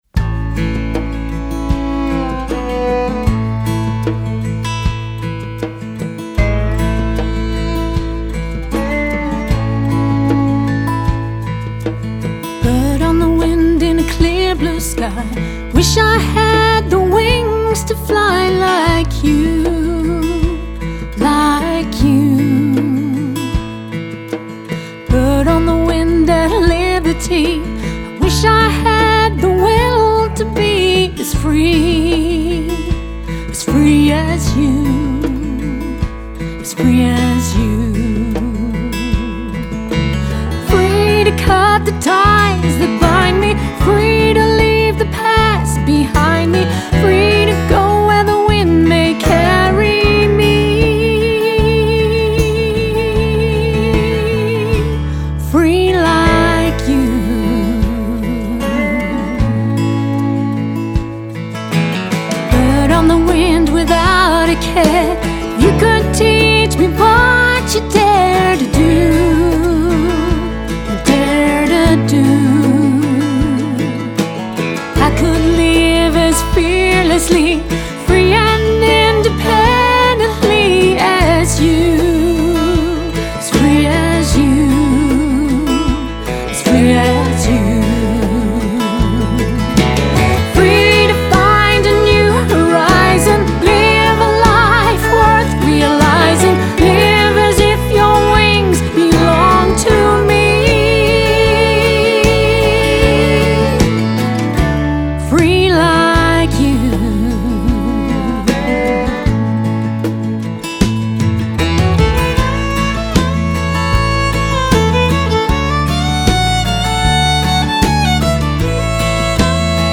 Hillbilly Hut in Sydney